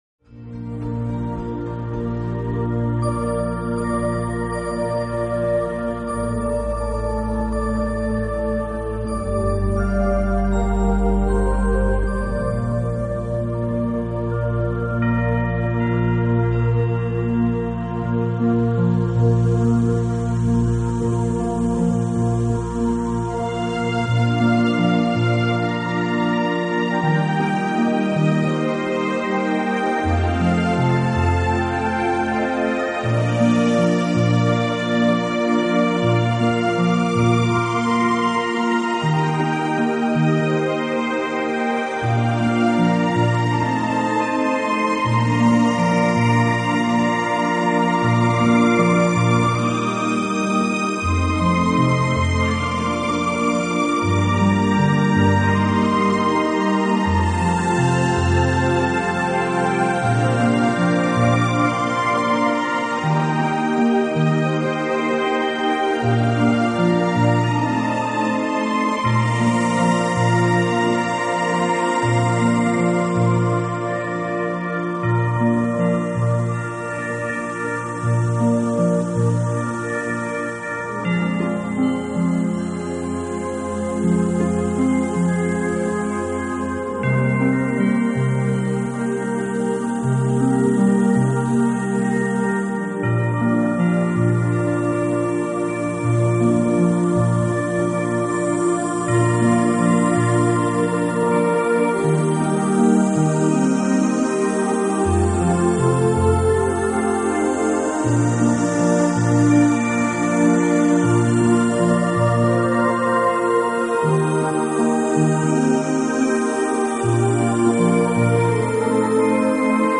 专辑中大部分曲目均以空灵女声演唱居多，个别两首曲目为男歌手或是童声，为节 日带来欢快祥和的气氛。